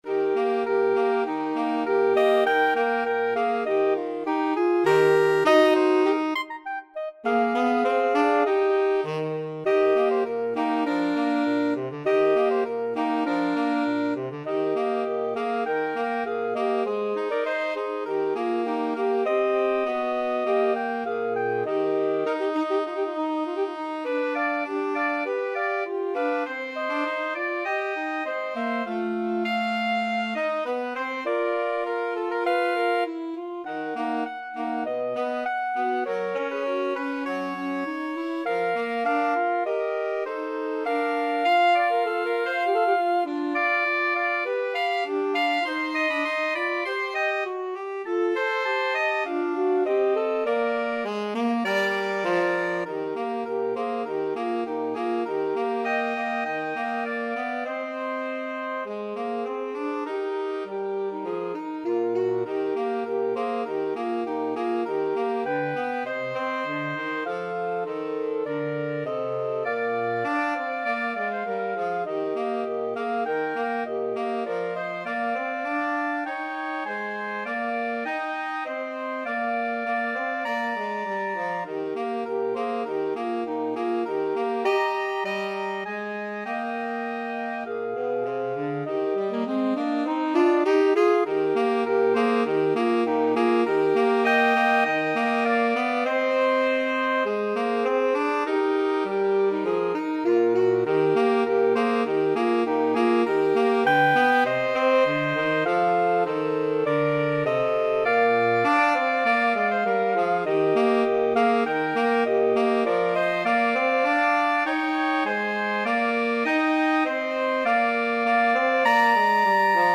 Soprano SaxophoneAlto SaxophoneTenor Saxophone
2/4 (View more 2/4 Music)
Tempo di Marcia
Pop (View more Pop Woodwind Trio Music)